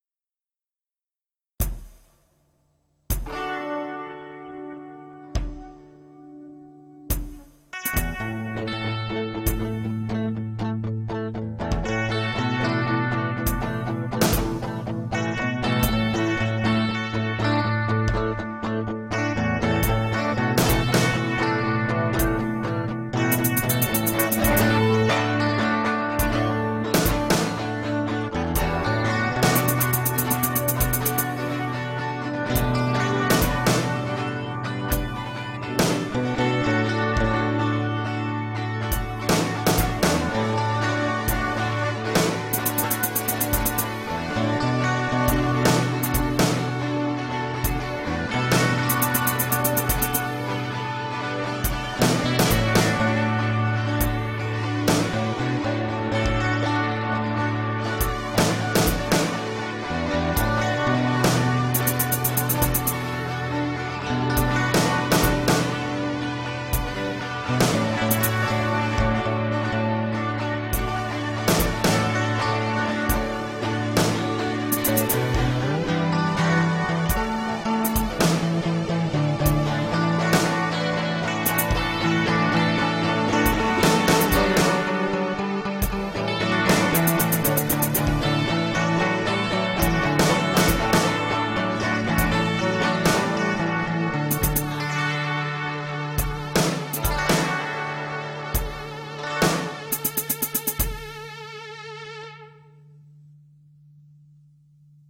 alternatív, experimentális, avantgarde, underground